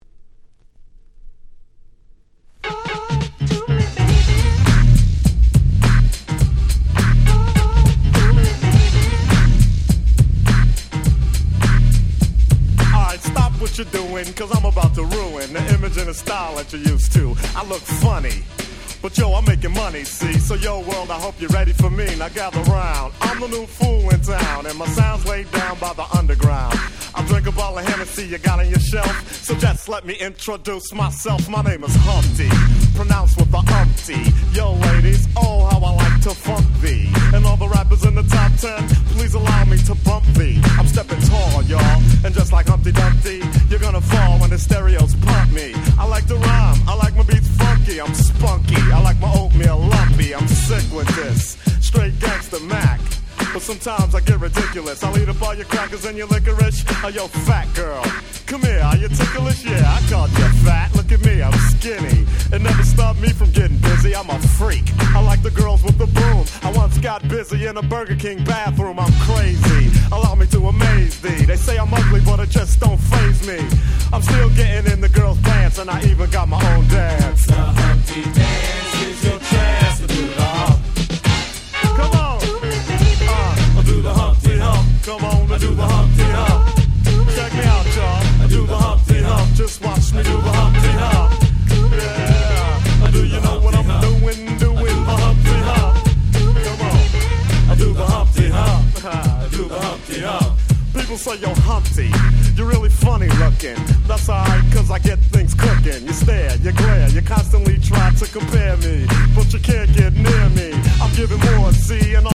89' Smash Hit Hip Hop !!
超Funkyなフロアヒット！！
Boom Bap